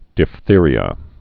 (dĭf-thîrē-ə, dĭp-)